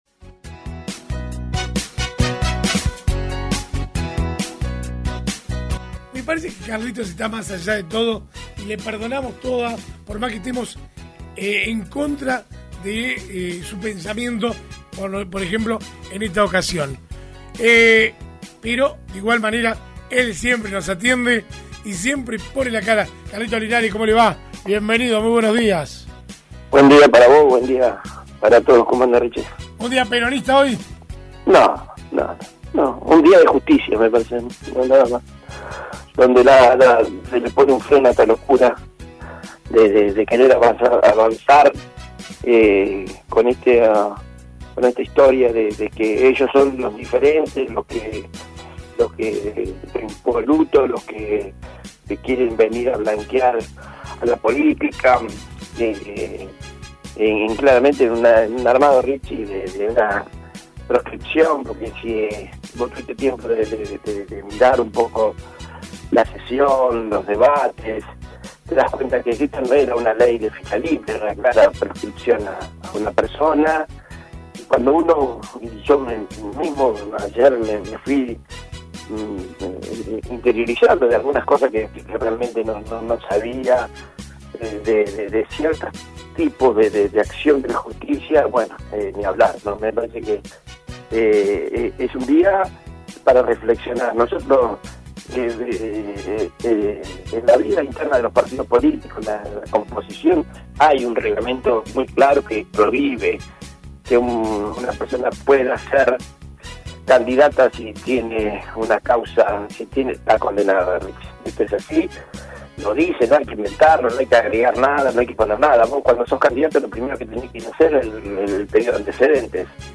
Esto contaba en el aire de RADIOVISIÓN: